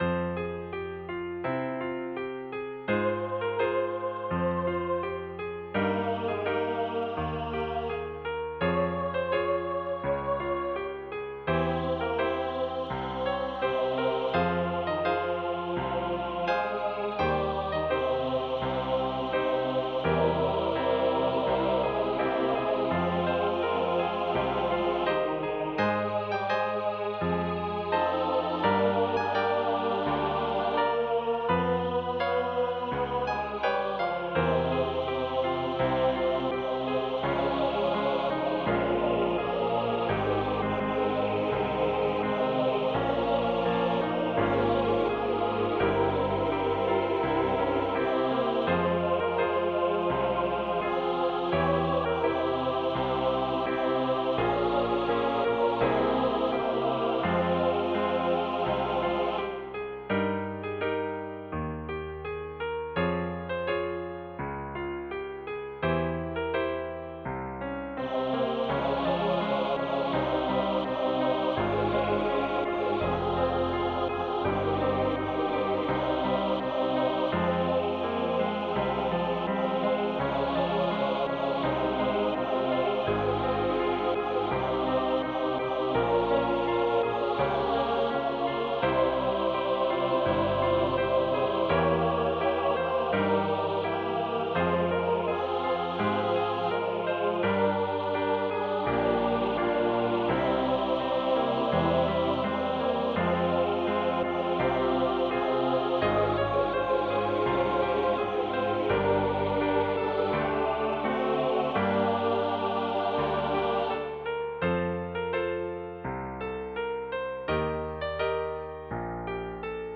Easter Anthem for SATB choir and piano is a dramatic setting